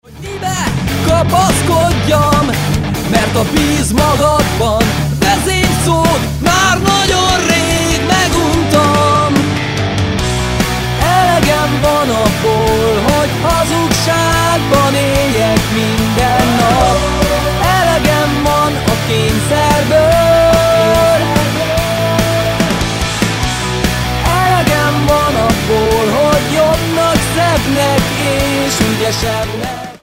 Rockos hangvétel